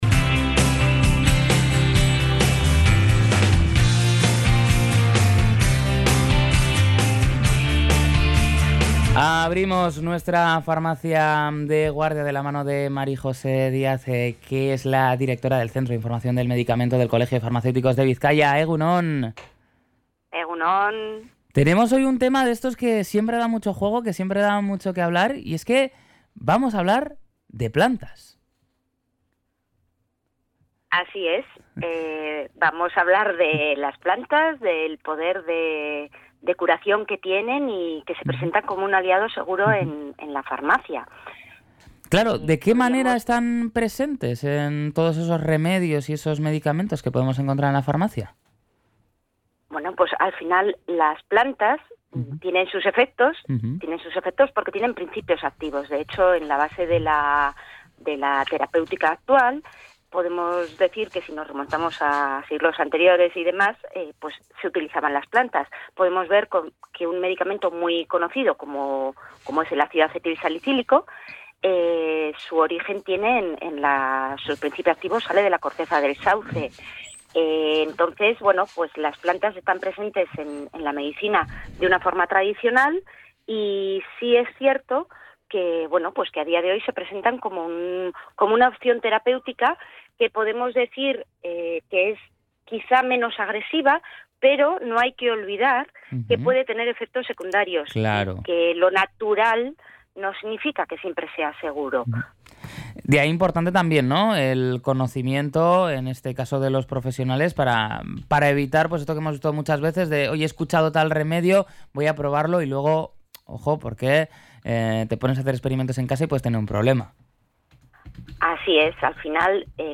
Durante su intervención en Egun On Bizkaia, dentro del espacio Farmacia de Guardia, ha insistido en que las plantas tienen principios activos y, por tanto, efectos y posibles reacciones adversas.